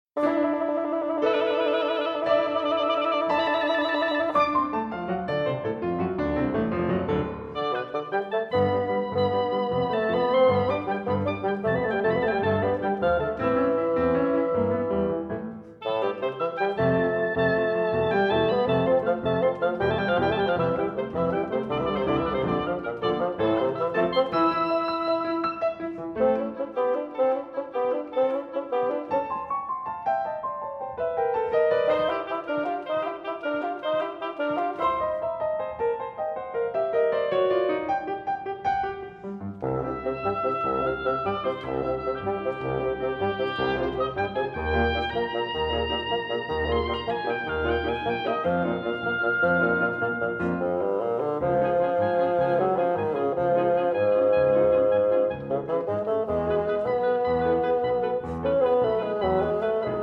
bassoon
Oboe